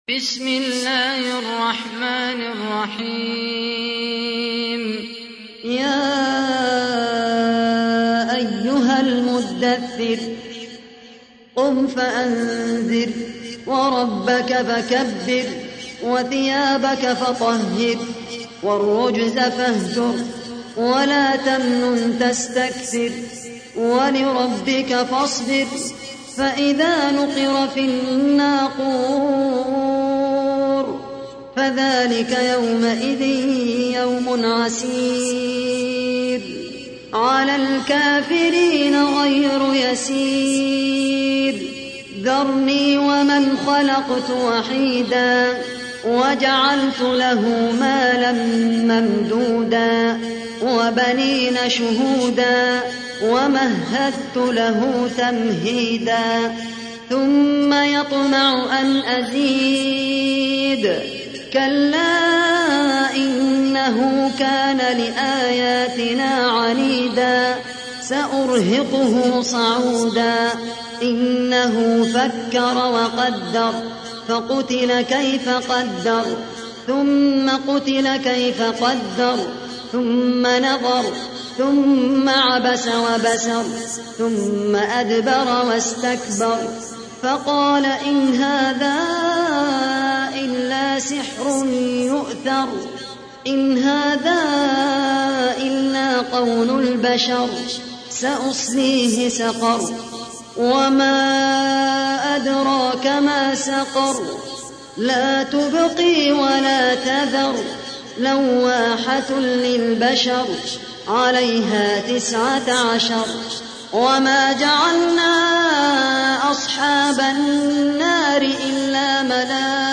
تحميل : 74. سورة المدثر / القارئ خالد القحطاني / القرآن الكريم / موقع يا حسين